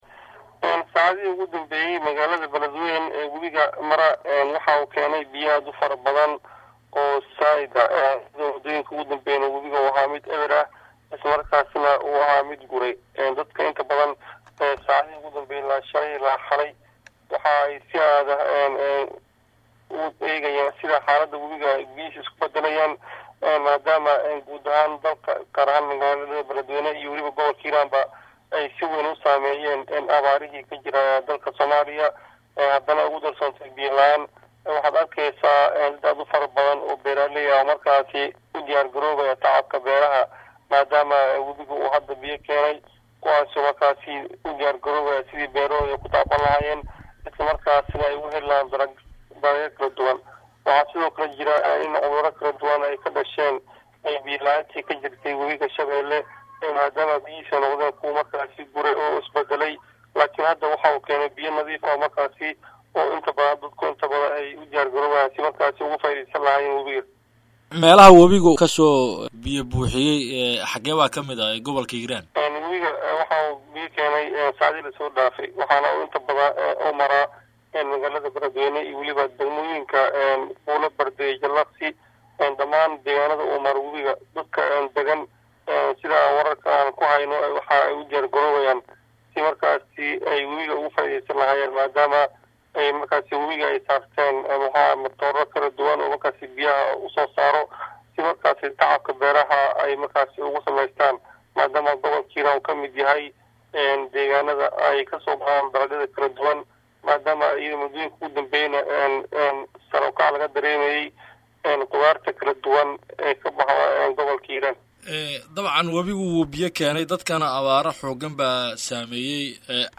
Dhageyso Warbixinta Wariyaheena